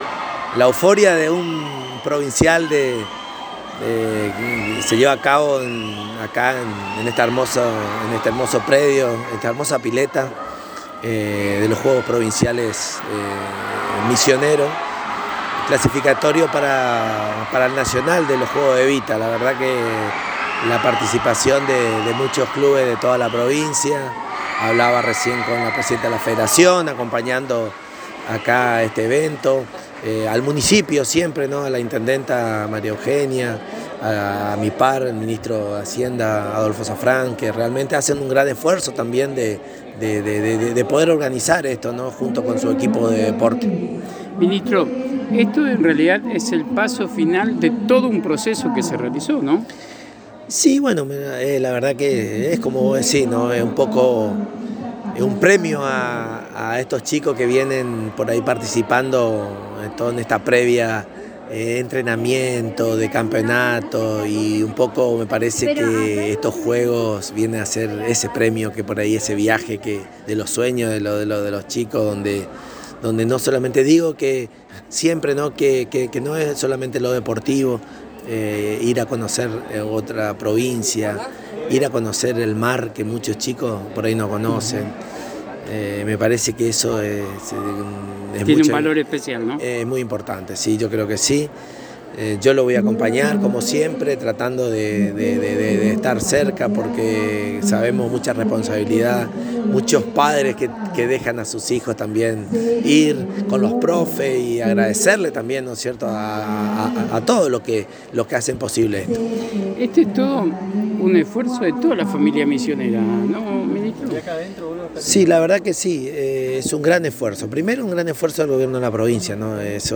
En la ciudad de Apóstoles cabecera del Departamento Apóstoles se realiza la final de natación Provincial clasificatorio para el Evita 2023 que se desarrollará en la Ciudad de Mar Del Plata en el mes de Septiembre del corriente año.
Una vez más el Ministro de Deportes de Misiones Hector Javier Corti visita a Apóstoles y en diálogo exclusivo con la ANG manifestó su gran alegría de estar presente en esta etapa clasificatoria en un predio tan bello y en tan buenas condiciones.
Claramente emocionado el Ministro señaló que los juegos Evita además de servir para el desarrollo deportivo de los adolescentes y jóvenes también son un gran incentivo conocer el mar por primera vez de parte de muchos de quienes compiten.